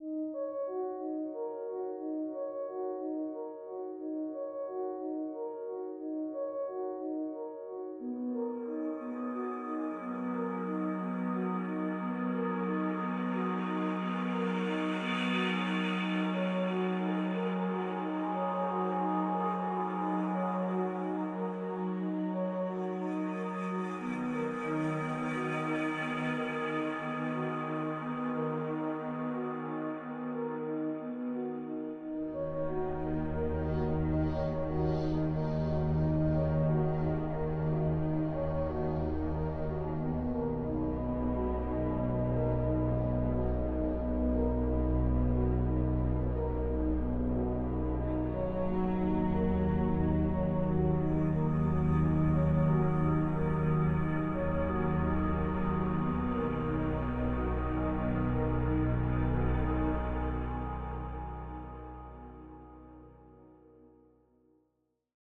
Added Ambient music pack. 2024-04-14 17:36:33 -04:00 18 MiB Raw Permalink History
Ambient Nothingness cut 60.wav